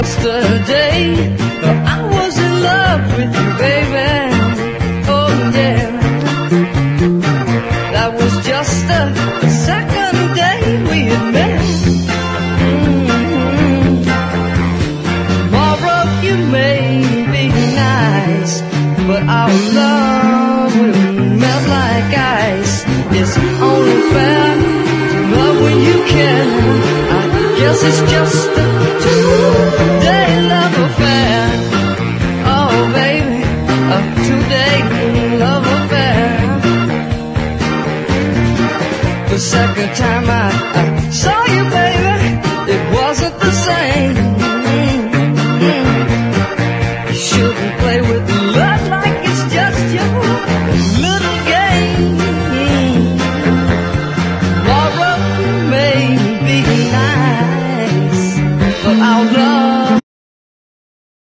NEW WAVE / ELECTRO
ガールズ・ラップも飛び出すN.Y.ファンキー・エレクトロ！